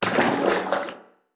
WaterSplash_In_Short3.wav